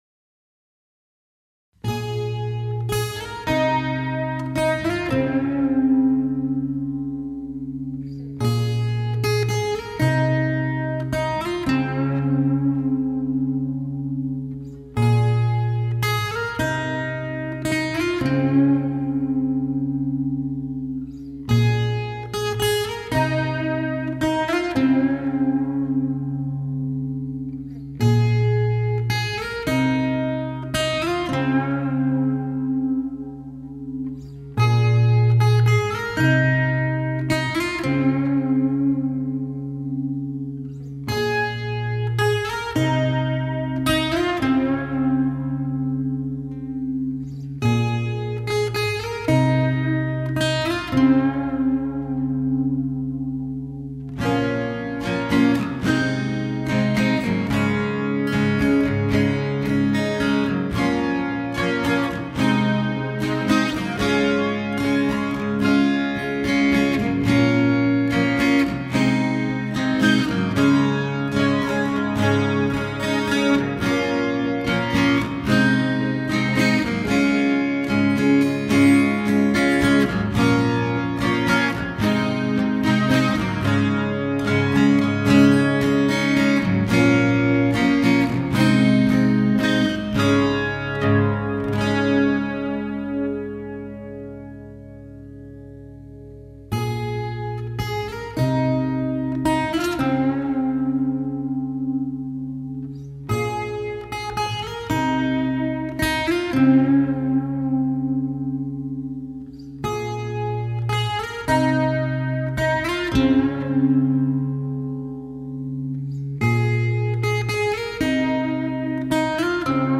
Rock & Roll
Rock/Hard-rock